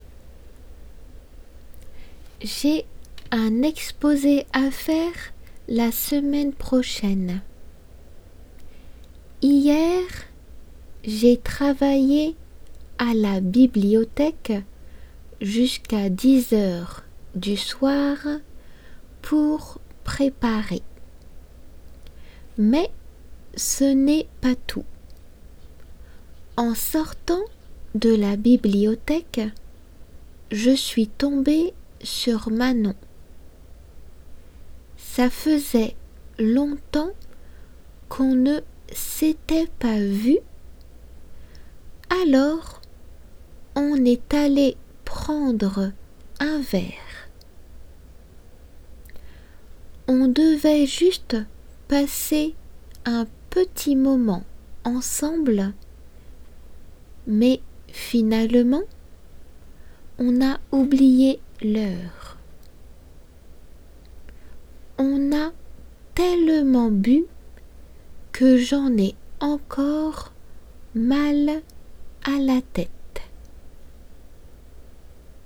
普通の速さで
仏検デイクテ0521m-rapide.m4a